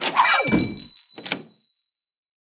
Store Heads General Activate Sound Effect